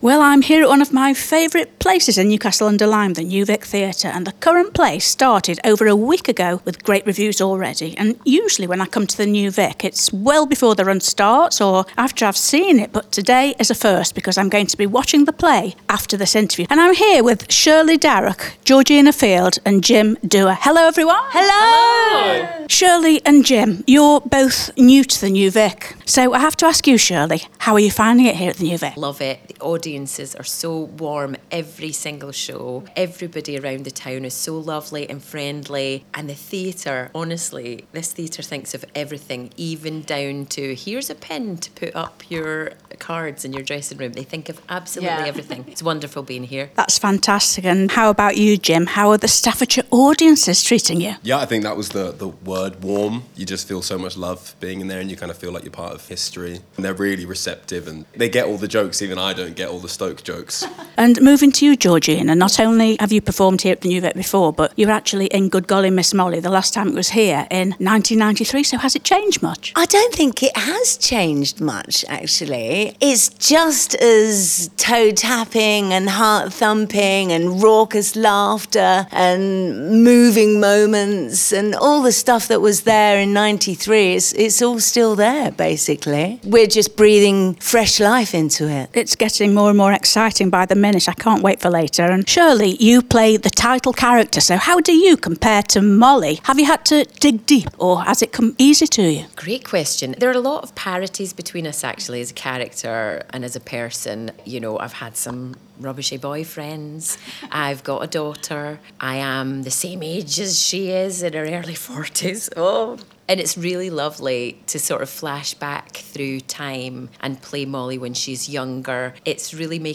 Good-Golly-Miss-Molly-Interview.wav